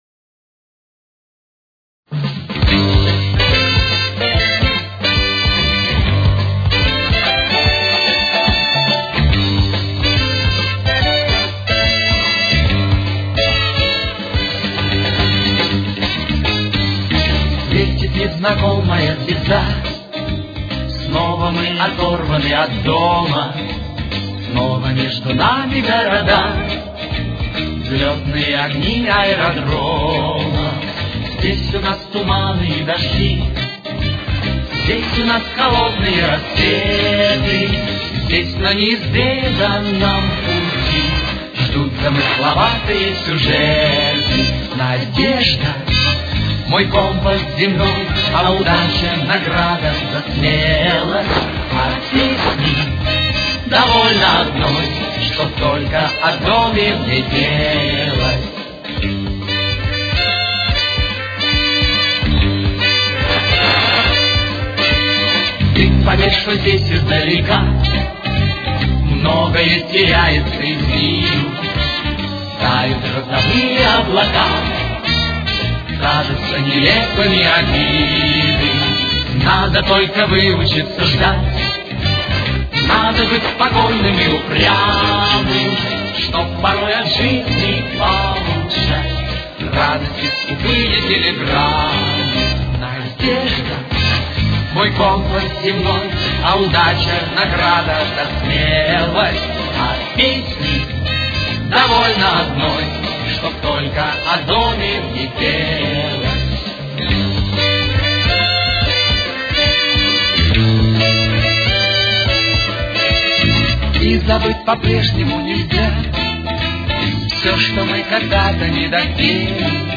с очень низким качеством (16 – 32 кБит/с)
Тональность: До минор. Темп: 71.